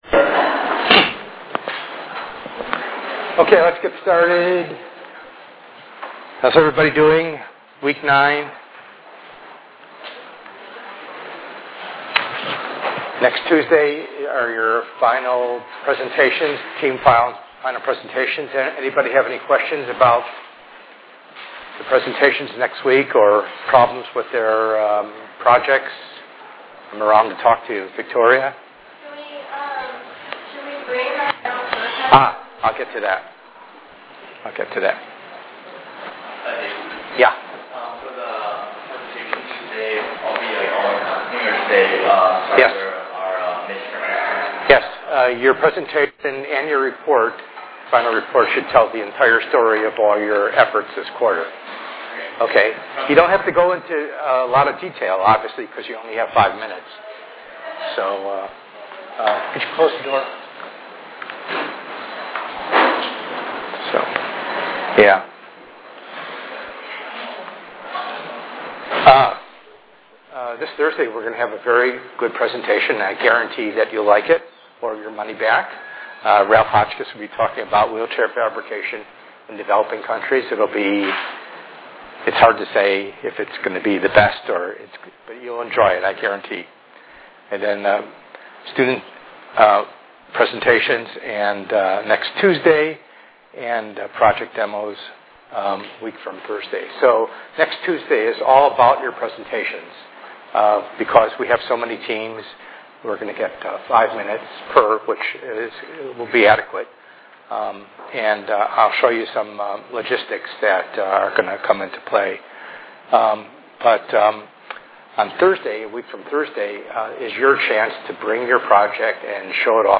ENGR110/210: Perspectives in Assistive Technology - Lecture 09a